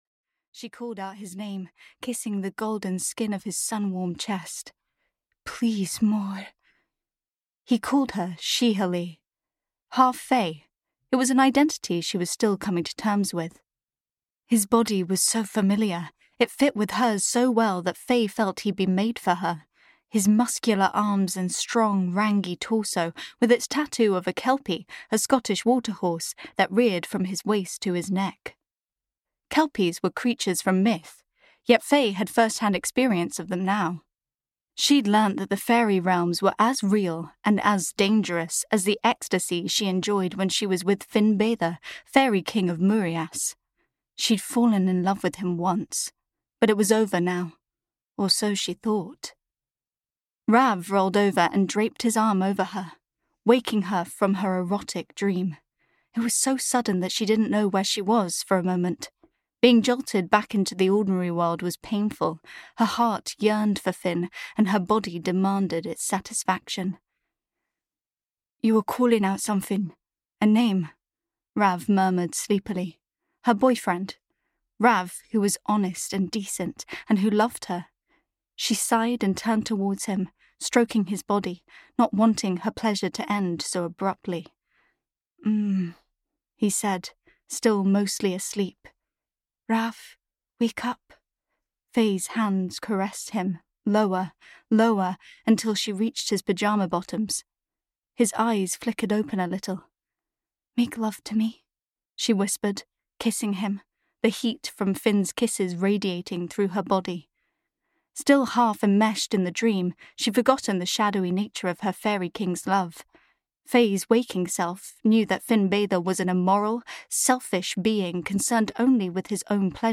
Queen of Sea and Stars (EN) audiokniha
Ukázka z knihy